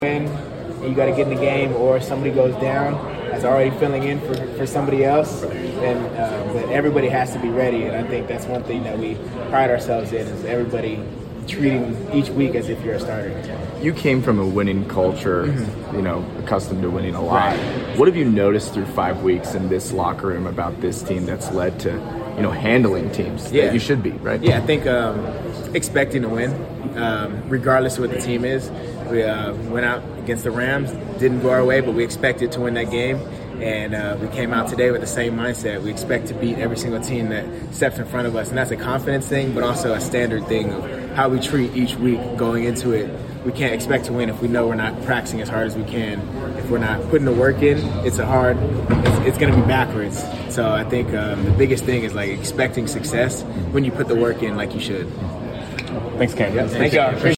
Indianapolis Colts Defensive Back Cam Bynum Postgame Interview after defeating the Las Vegas Raiders at Lucas Oil Stadium.